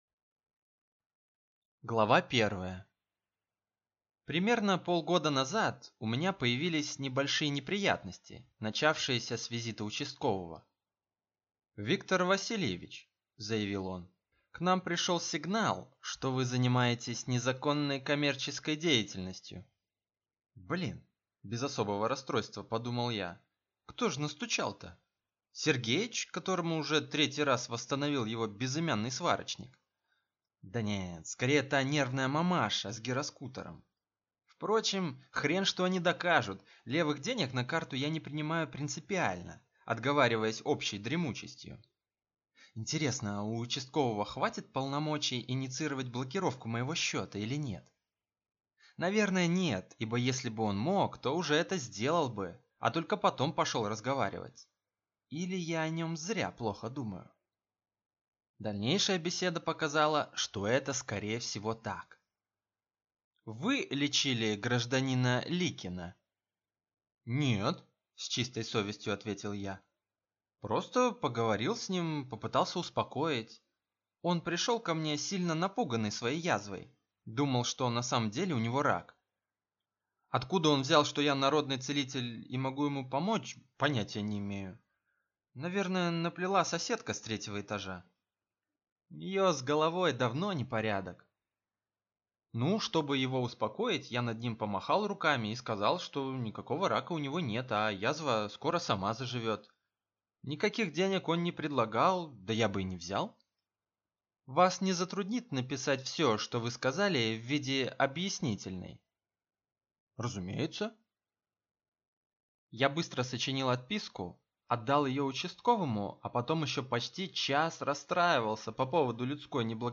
Аудиокнига Фагоцит. За себя и за того парня | Библиотека аудиокниг
Прослушать и бесплатно скачать фрагмент аудиокниги